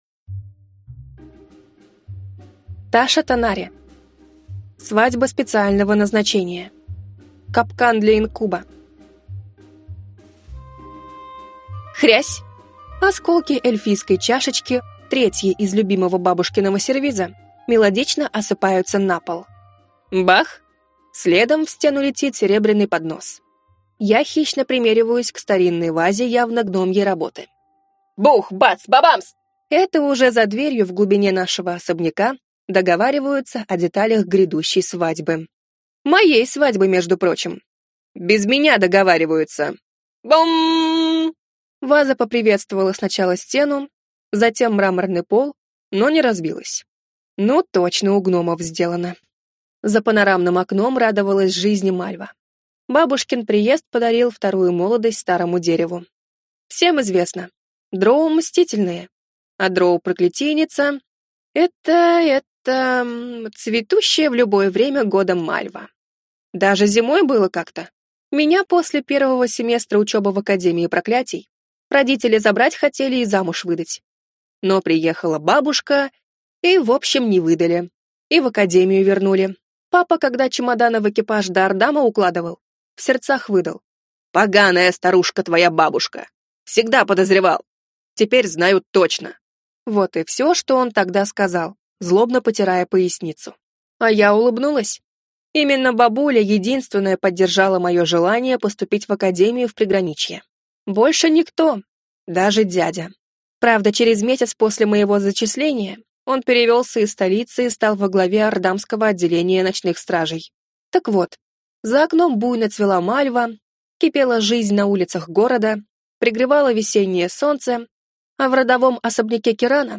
Аудиокнига Свадьба специального назначения. Капкан для инкуба | Библиотека аудиокниг